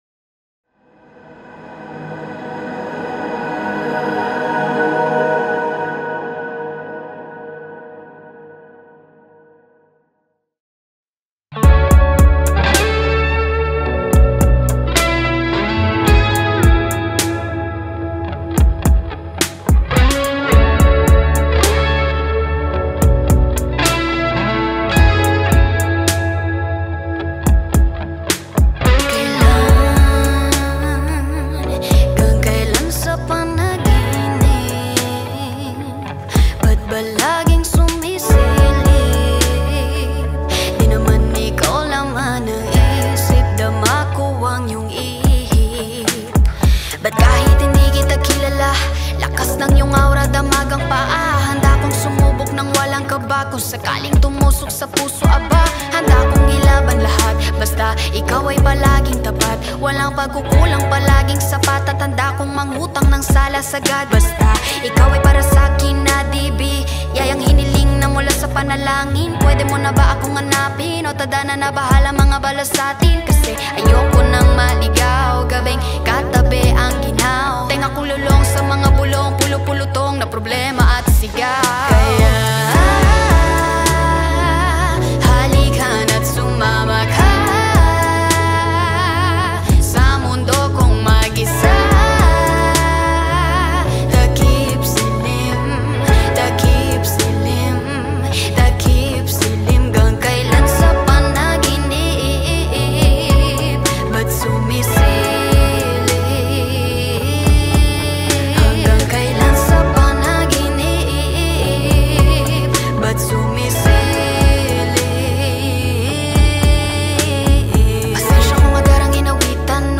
Keyboardist
Guitarist
Bassist